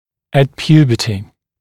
[ət ‘pjuːbətɪ][эт ‘пйу:бэти]при достижении половой зрелости, в период достижения половой зрелости